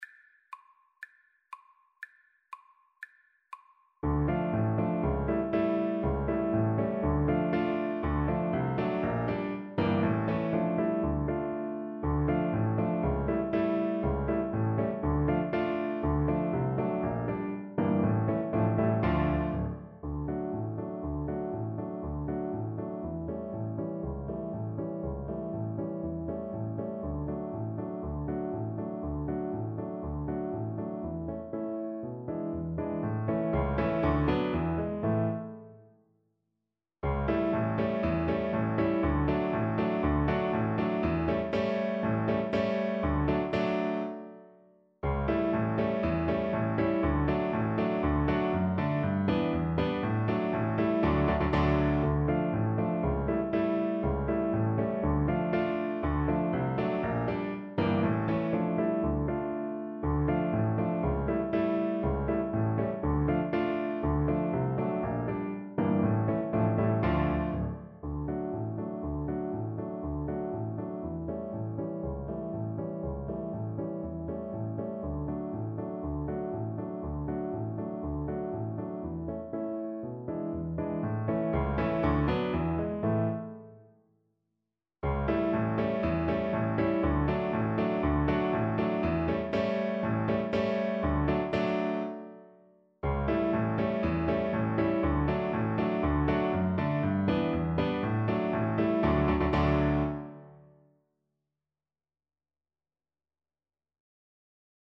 Classical (View more Classical Flute Music)